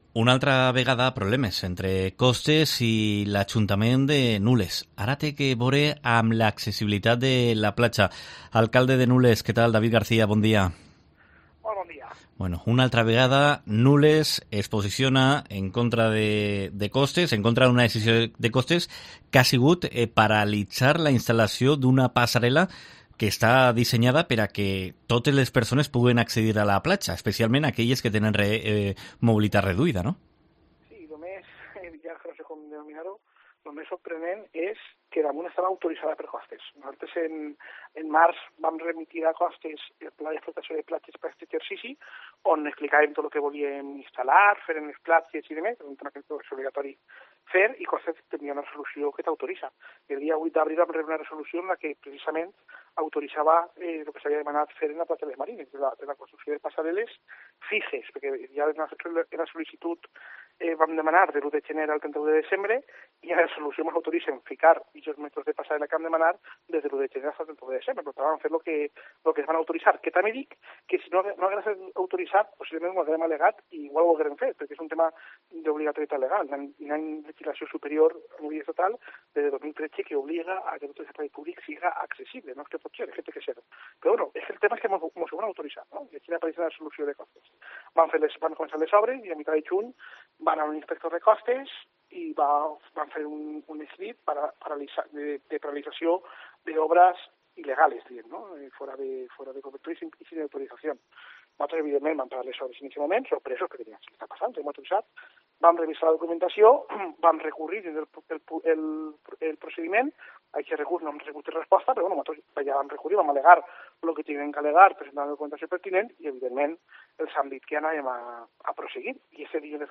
AUDIO: Nules vuelve a plantar cara a Costas tras no permitir instalar una pasarela en la playa, según explica a COPE el alcalde, David García
Entrevista